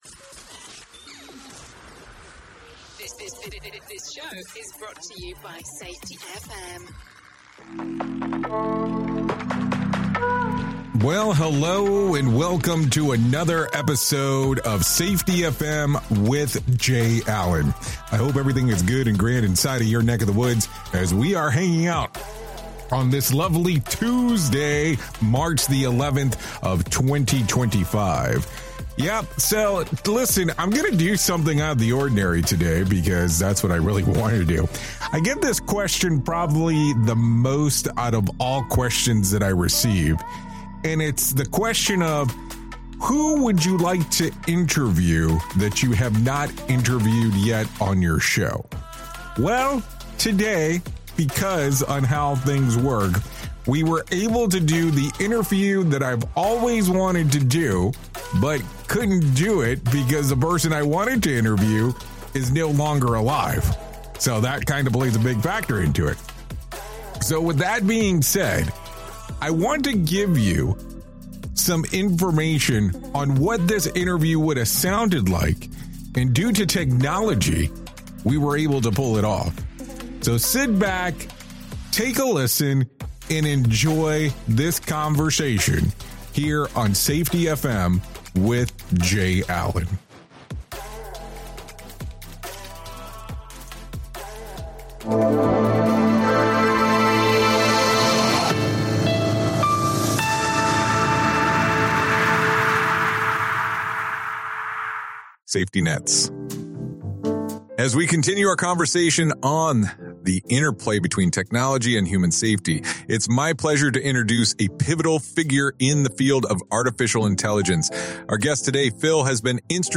1 EP 704 - The Interview I’ve Always Wanted: Safety, Perception, and the Hidden Layers of Reality 42:58 Play Pause 8h ago 42:58 Play Pause Putar nanti Putar nanti Daftar Suka Menyukai 42:58 After years of exploring the world of safety, communication, and human performance, this is the interview I’ve always wanted to do.